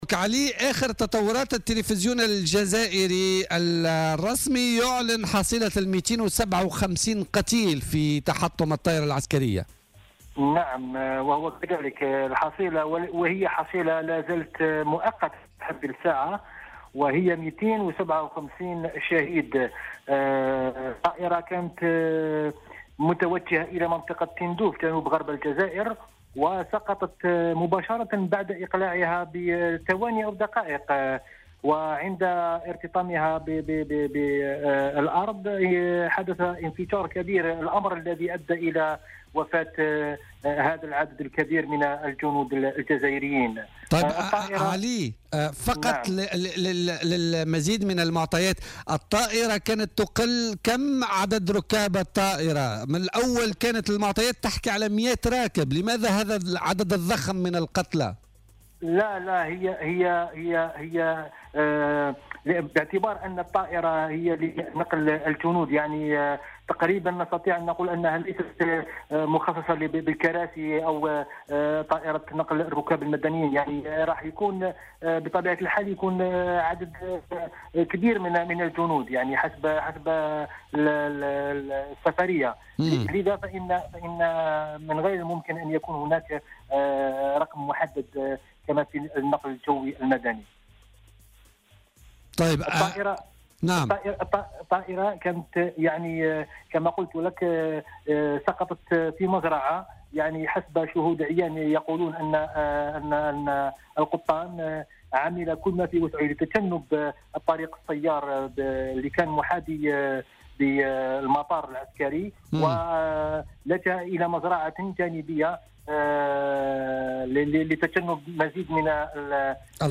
وأضاف في مداخلة له اليوم في برنامج "بوليتيكا" أن الطائرة كانت متوجهة نحو تندوف وتحطمت دقائق بعد إقلاعها في منطقة بعيدة عن المناطق السكنية (مزرعة) وأسفرت كذلك عن إصابة 6 أشخاص كانوا متواجدين بالقرب من مكان سقوطها. وأشار إلى وجود معلومات تفيد بأن من بين الضحايا 26 عضوا من البوليساريو، مؤكدا أن عديد الوزراء والمسؤولين اضطروا إلى قطع زيارات كانت مبرمجة ونشاطات بسبب هذا الحادث.